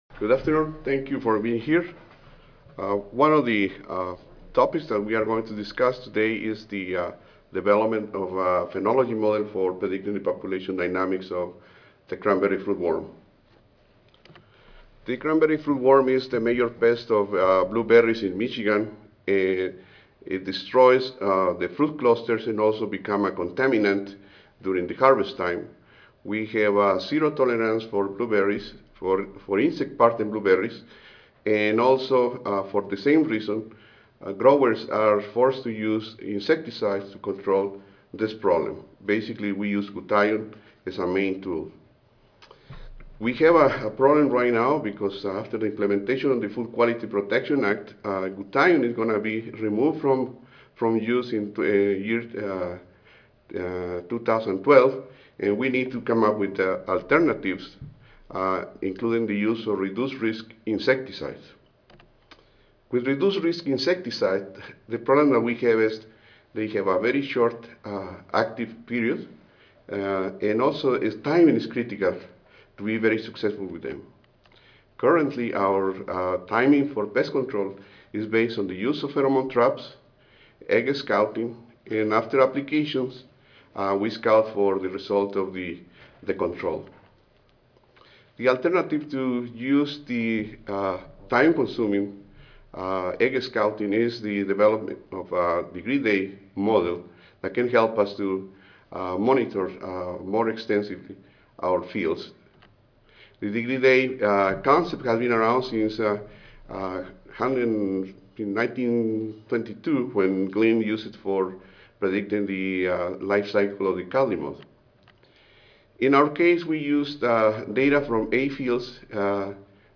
Ten Minute Paper (TMP) Oral